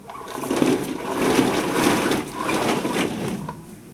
Bajar una persiana
persiana
Sonidos: Acciones humanas
Sonidos: Hogar